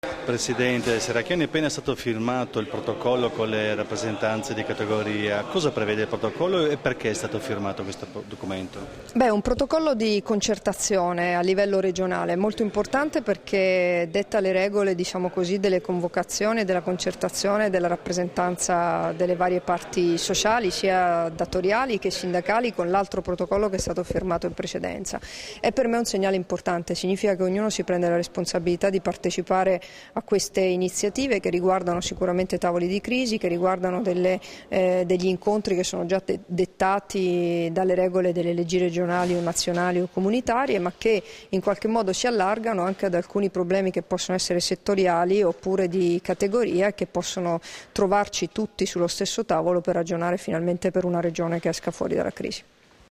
Dichiarazioni di Debora Serracchiani (Formato MP3) [855KB]
rilasciate a margine del Tavolo per la firma del "Protocollo sulla politica regionale della concertazione" con rappresentanti delle categorie economiche, a Udine il 28 ottobre 2013